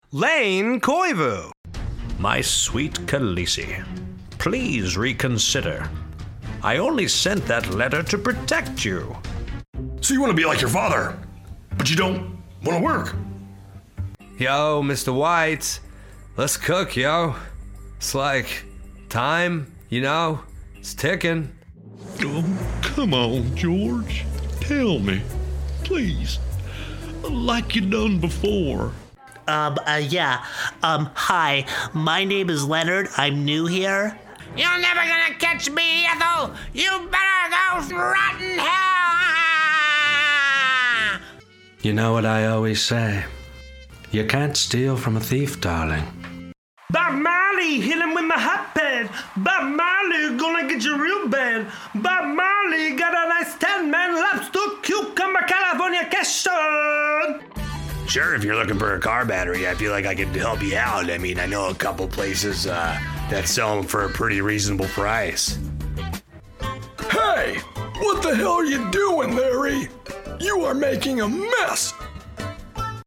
My VO Reel!
A sample of my VO work!